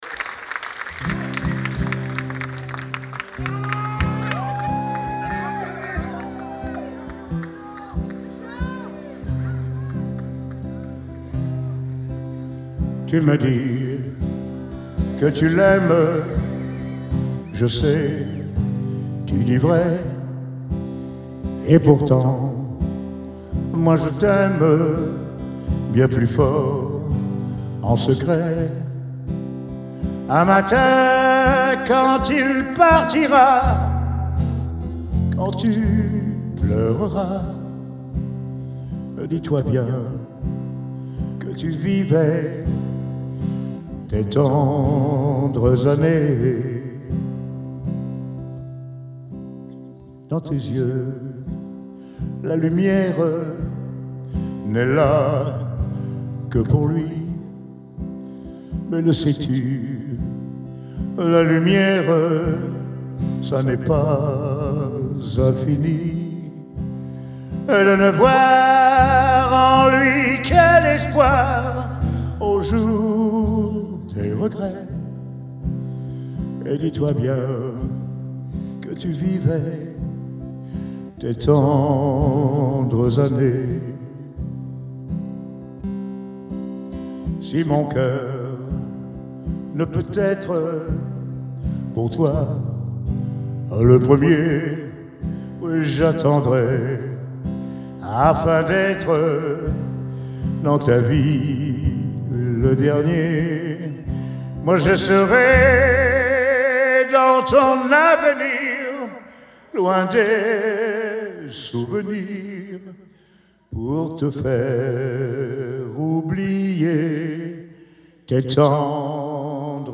Genre : French Music